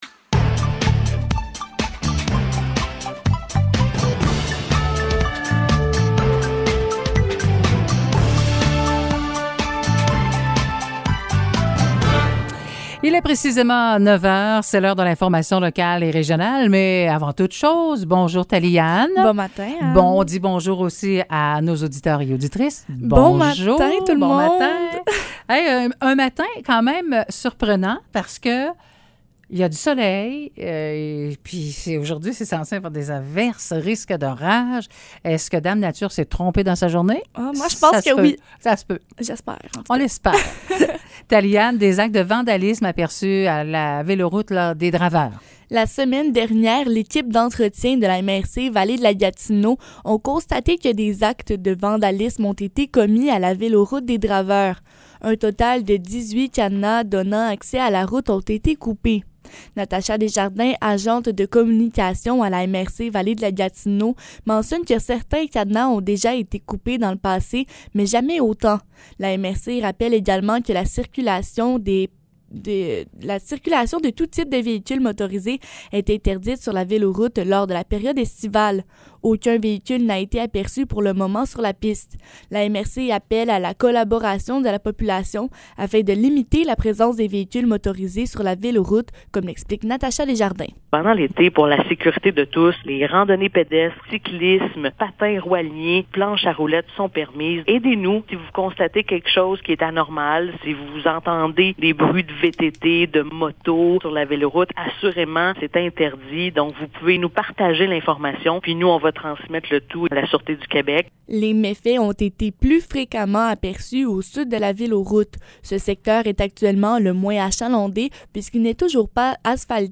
Nouvelles locales - 1er juin 2022 - 9 h